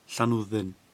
Welsh pronunciation) is a village and community in Montgomeryshire, Powys, Wales.
Cy-Llanwddyn.ogg.mp3